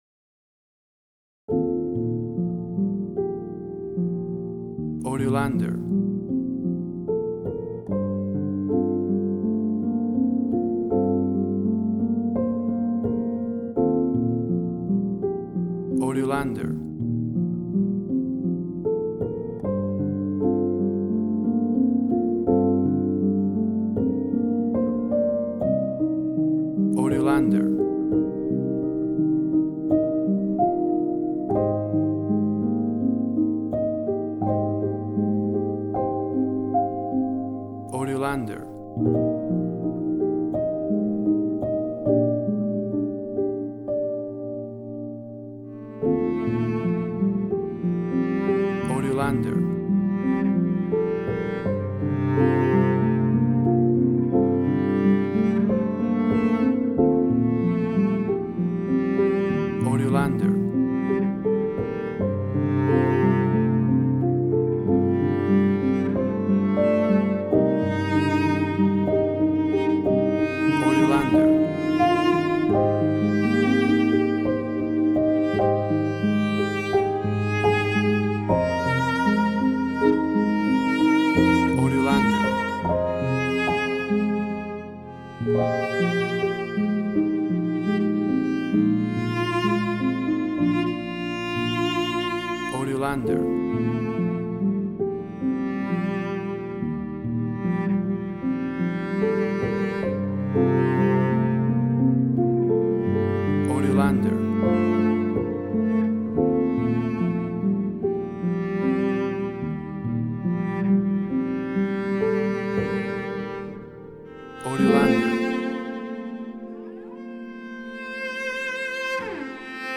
WAV Sample Rate: 16-Bit stereo, 44.1 kHz
Tempo (BPM): 80